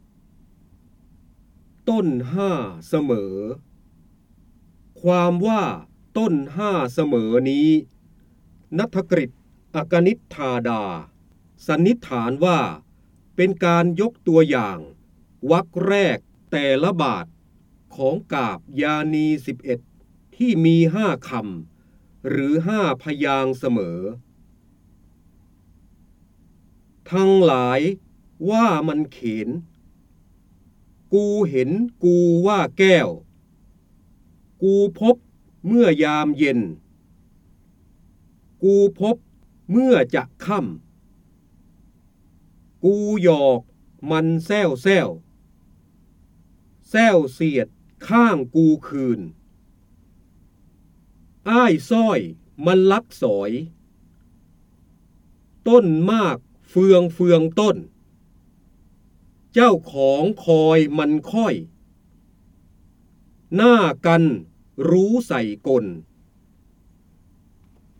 เสียงบรรยายจากหนังสือ จินดามณี (พระโหราธิบดี) ต้นห้าเสมอ
คำสำคัญ : การอ่านออกเสียง, ร้อยกรอง, จินดามณี, พระโหราธิบดี, ร้อยแก้ว, พระเจ้าบรมโกศ